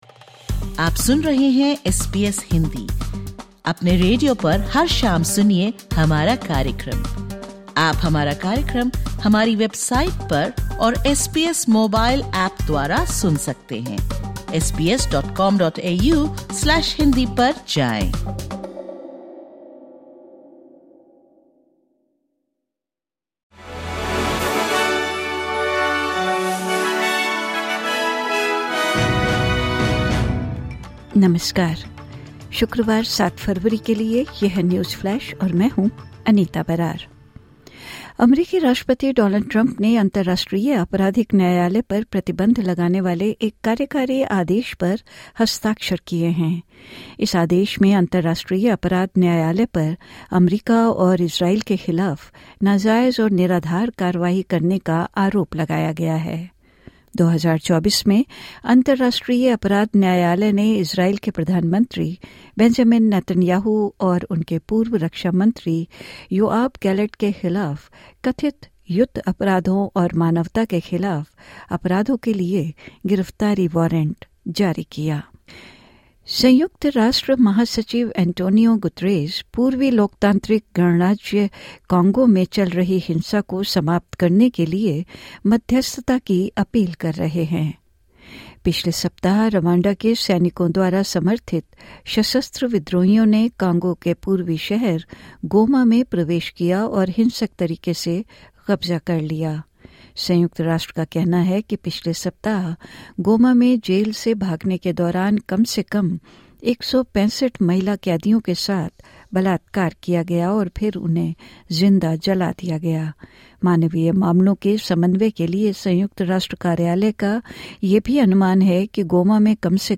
Listen to the top News of 07/02/2025 from Australia in Hindi.